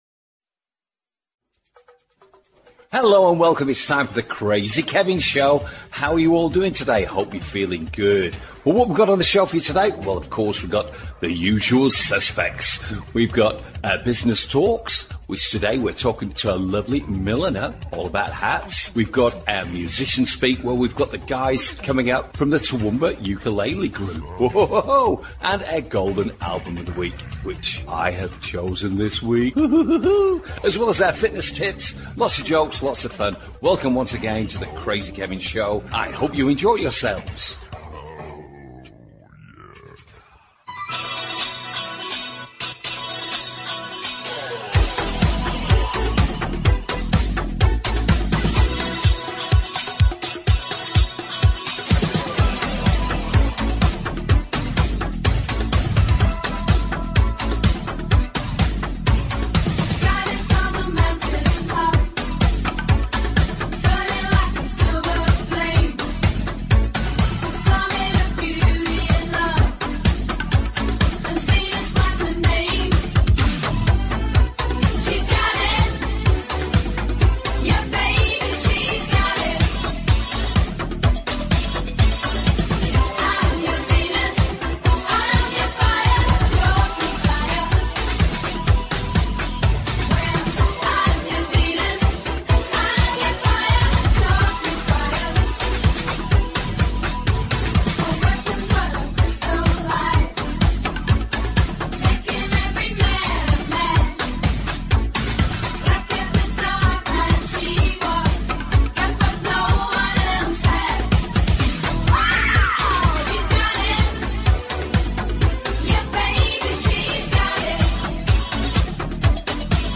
Plus lots of fun and great music!